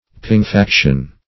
Search Result for " pinguefaction" : The Collaborative International Dictionary of English v.0.48: Pinguefaction \Pin`gue*fac"tion\, n. [L. pinguefacere, pinguefactum, to fatten; pinguis fat + facere to make.]
pinguefaction.mp3